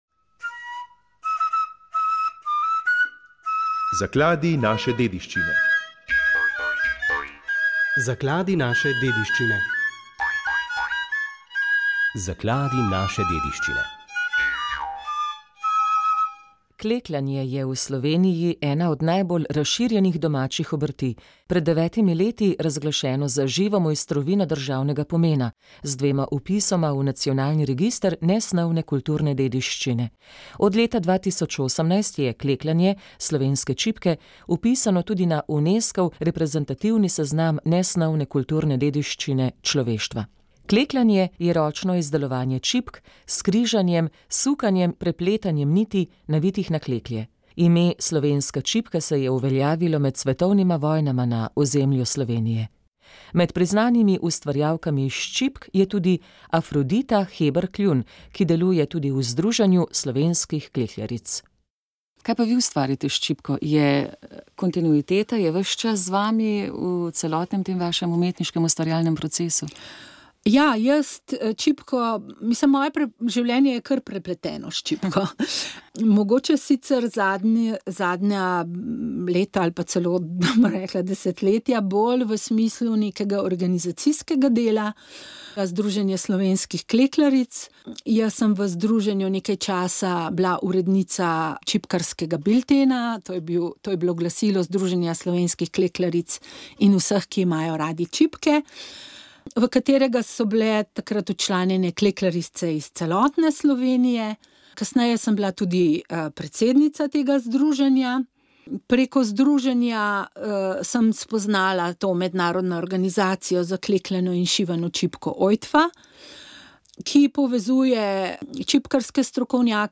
V 16. epizodi podkasta RAST smo gostili dr. Jožeta Podgorška, predsednika Kmetijsko gozdarske zbornice Slovenije. Prisluhnite, kako vodenje te največje kmečke stanovske organizacije vidi po letu dni »zelo razburkane plovbe« in kakšni so izzivi, ki jih napovedujejo pretresi na kmetijskem področju v prihodnjem letu.